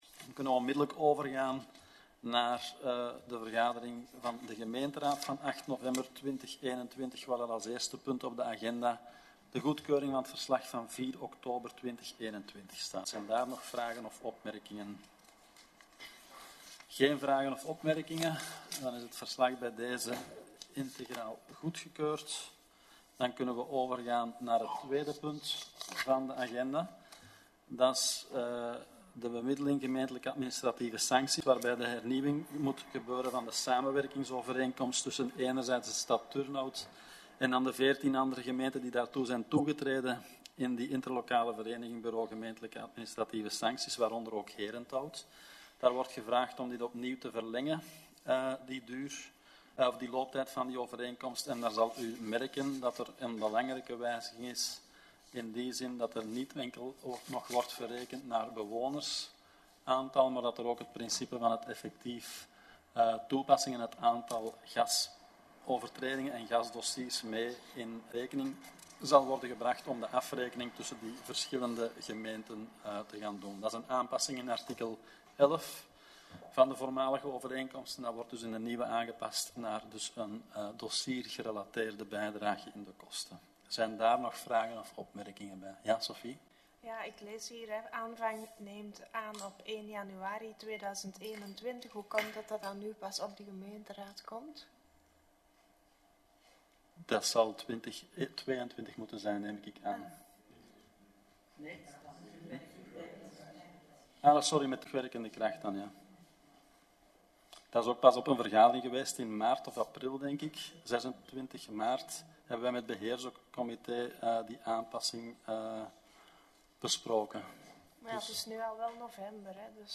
Gemeenteraad 8 november 2021